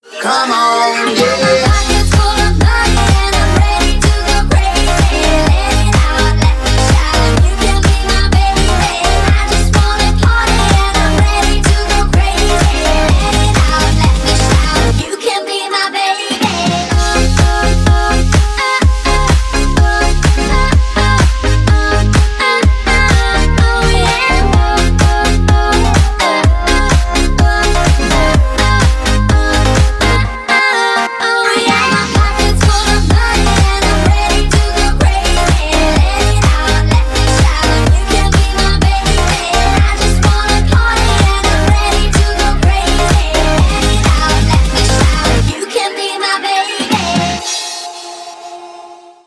• Качество: 320, Stereo
веселые
сумасшедшие